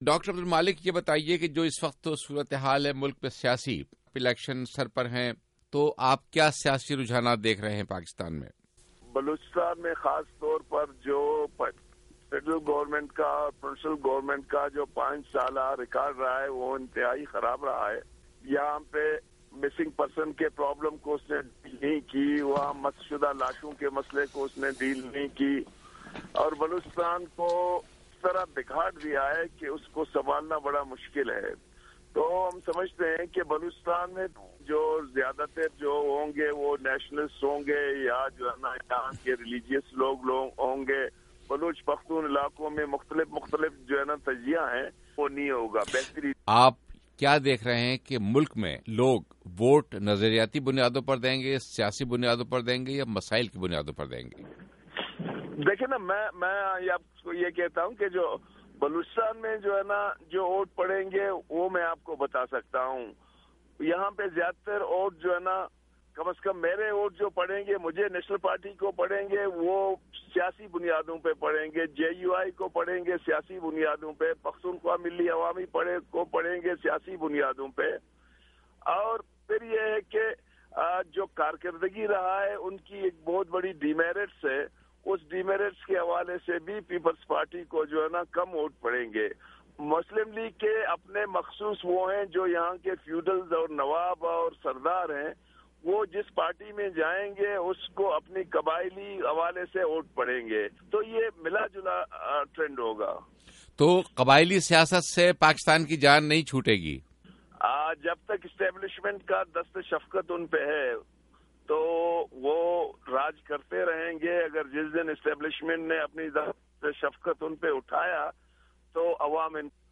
’وائس آف امریکہ‘ کی اردو سروس سےایک انٹرویو میں اُنھوں نےکہا کہ وہ بلوچ باغیو ں سے مذاکرات کے امکانات کے بارے میں پُر امید ہیں۔
نیشنل پارٹی کے صدر، ڈاکٹر عبد المالک سے گفتگو